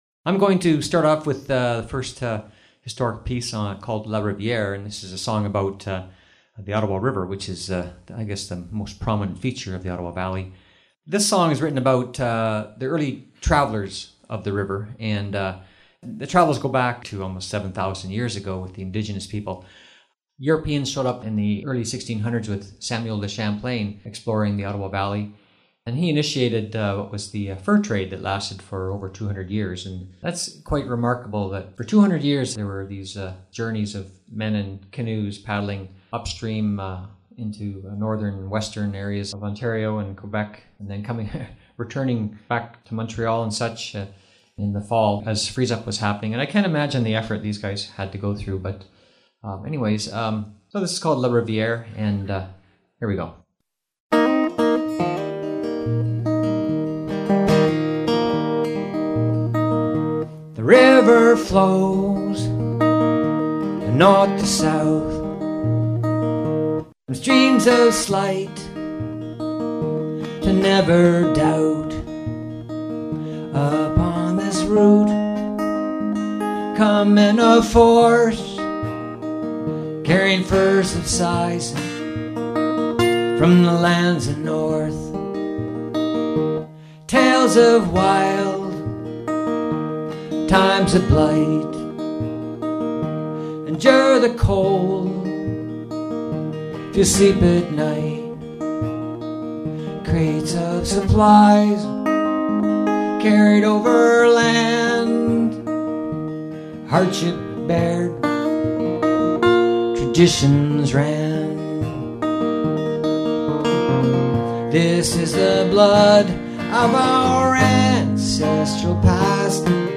Recording Location: Bonnechere Valley, ON
story teller and singer